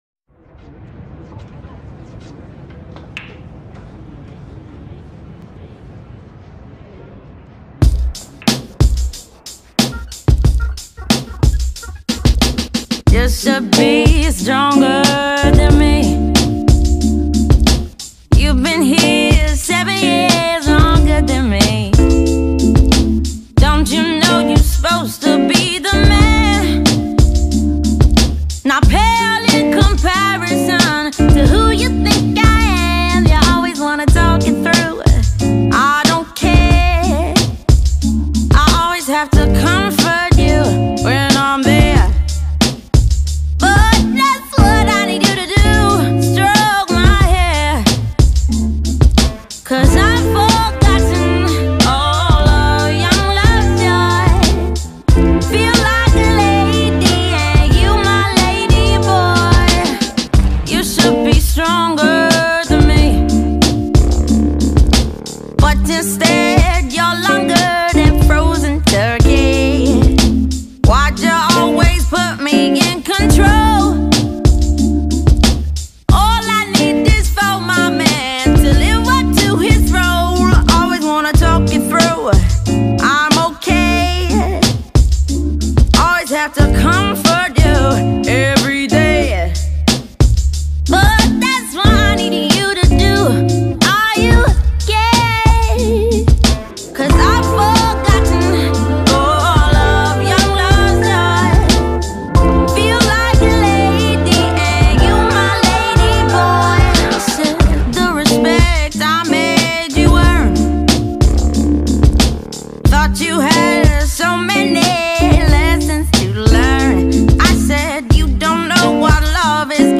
Soul, Jazz